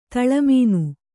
♪ taḷa mīnu